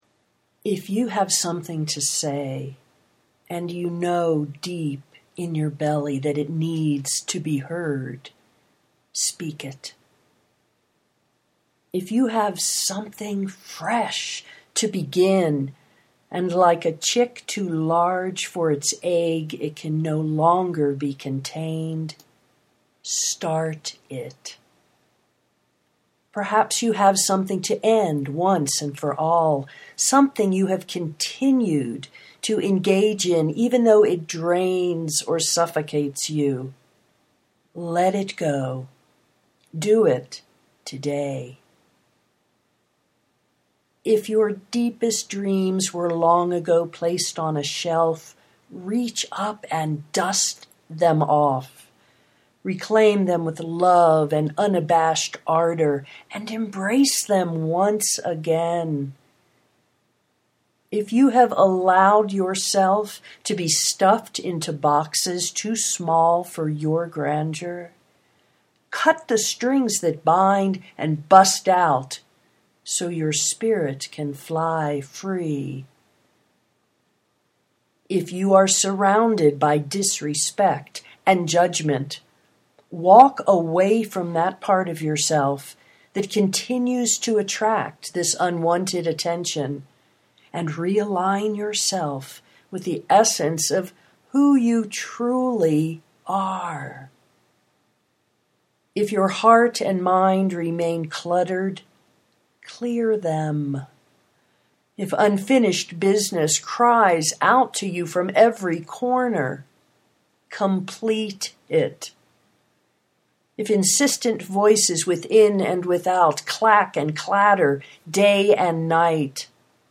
do it today (audio poetry 3:55)